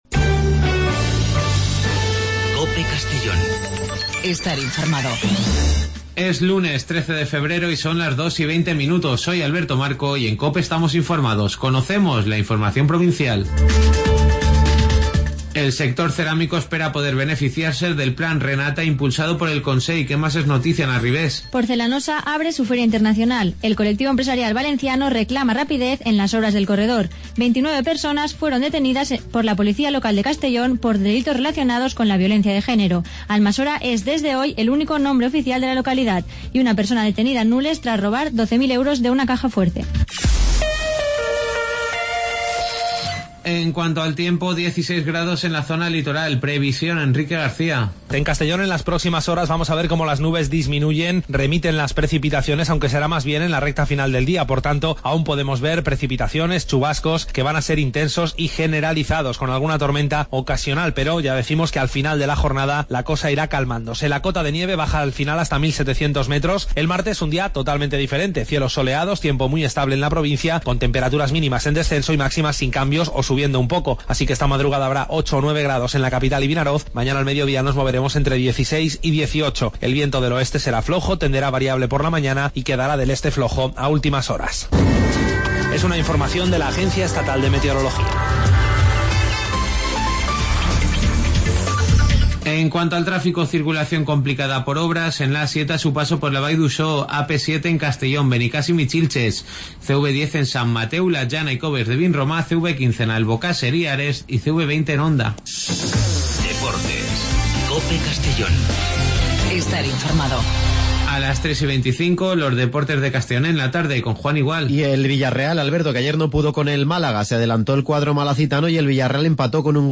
Las noticias del día de 14:20 a 14:30 en Informativo Mediodía COPE en Castellón.